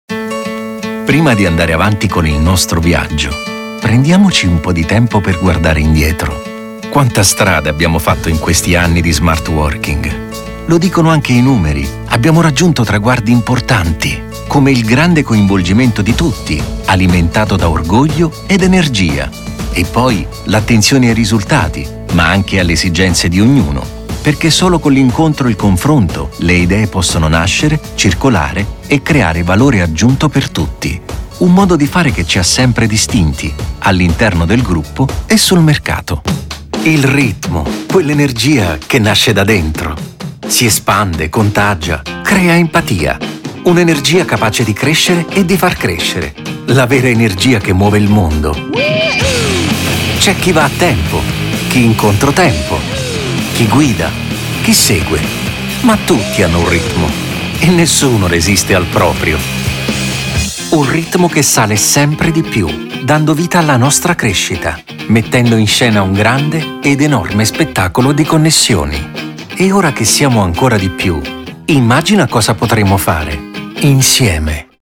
Corporate Videos
My recording room features state-of-the-art acoustic treatment, ensuring crystal-clear and broadcast-quality audio.